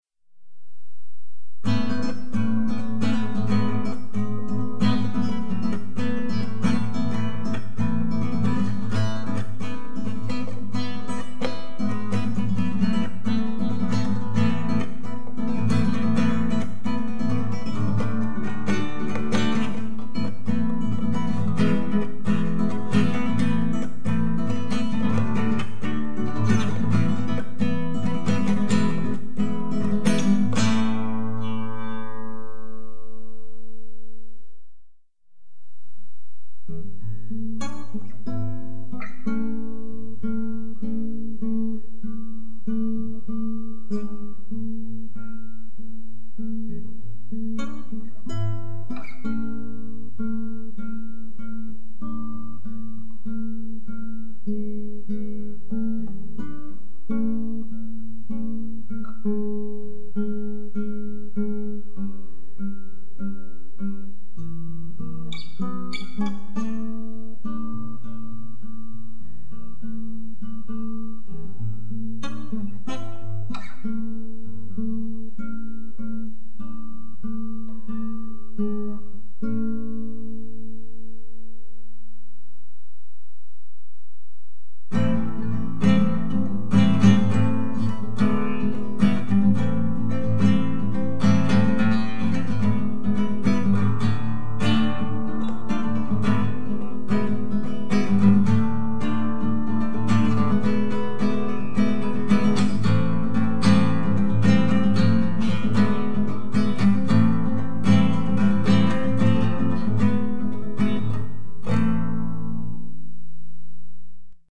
Anyways here is part two of my guitar doodles.
guitar_doodles_02.mp3